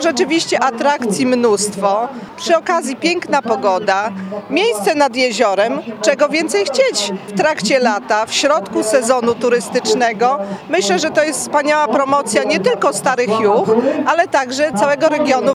Jak mówi Jolanta Piotrowska, członek zarządu województwa, takie wydarzenie to promocja całego regionu.